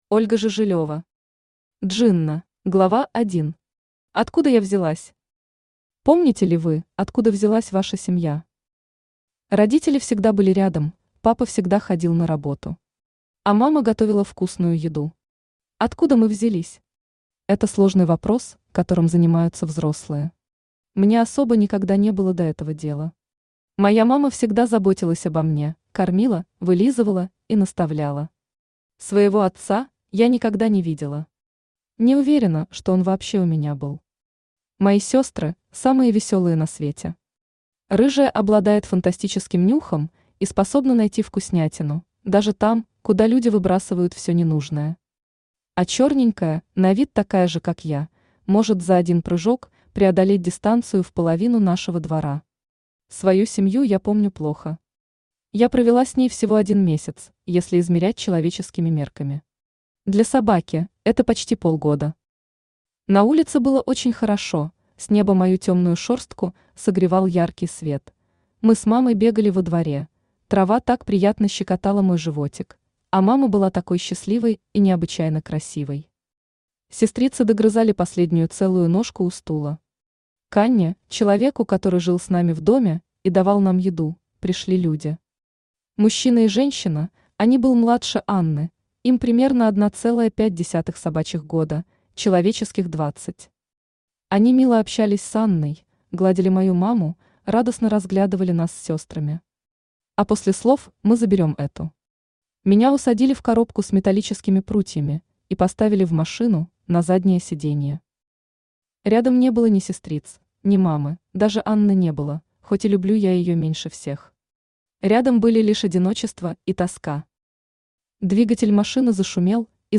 Aудиокнига Джинна Автор Ольга Жижилева Читает аудиокнигу Авточтец ЛитРес. Прослушать и бесплатно скачать фрагмент аудиокниги